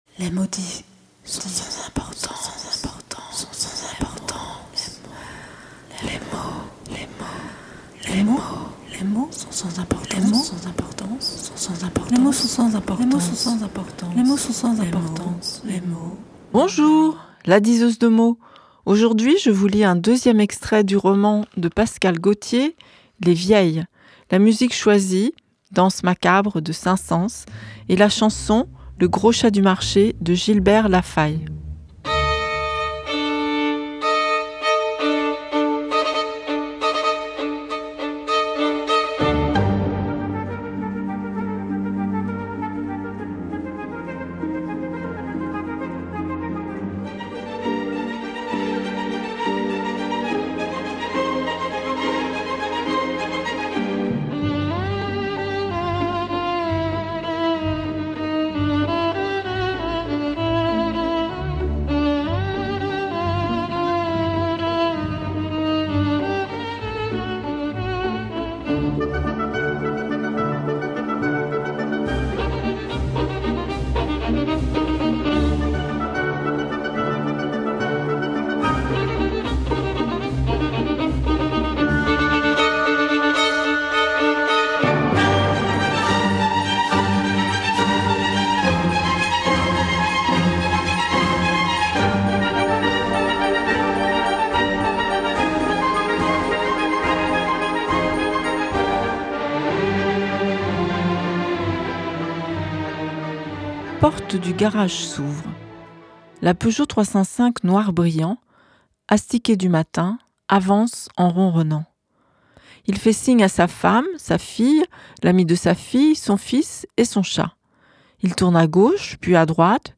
2nd extrait de Les Vieilles de Pascale Gautier
Musique : Danse Macabre de Saint-Saëns par National Philharmonic Orchestra Documents joints 2nd de Les Vieilles (MP3 - 35.5 Mo) SALON D'ECOUTE Aucun audio !